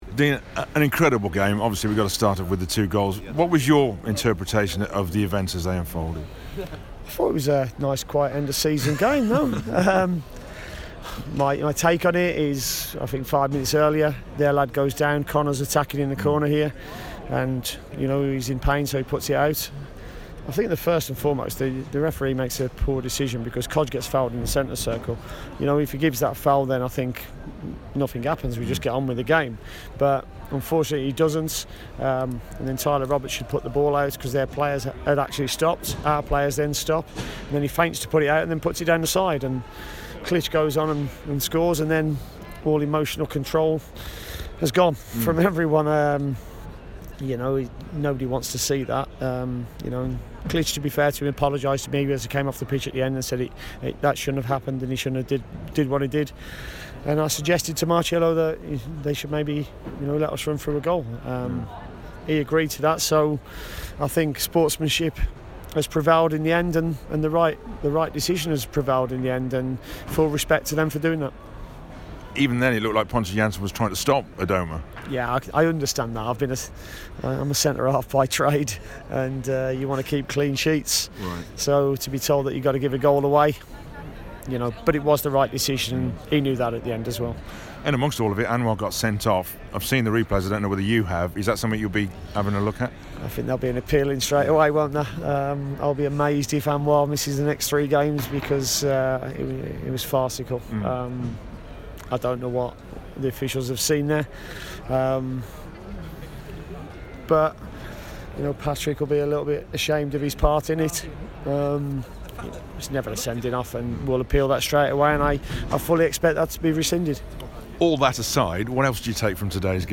The Villa boss talks to BBC WM after an eventful afternoon at Elland Road.